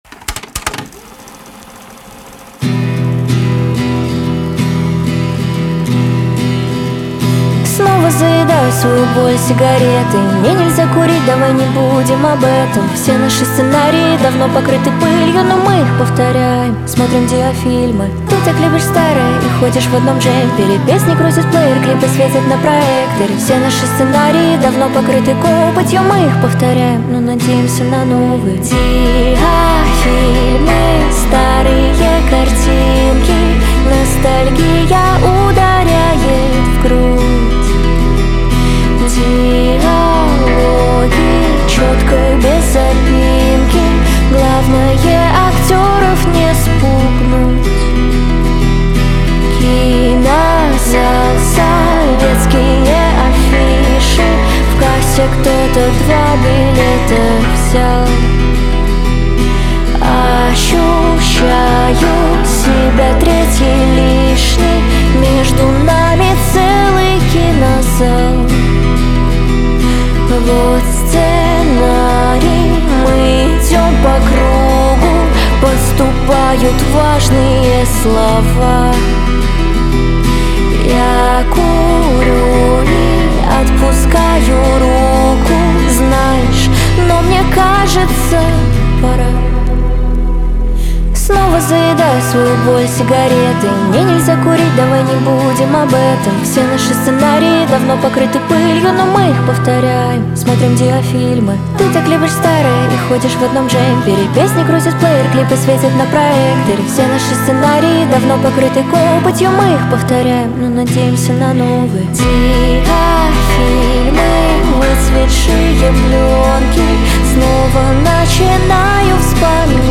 Качество: 320 kbps, stereo
Поп музыка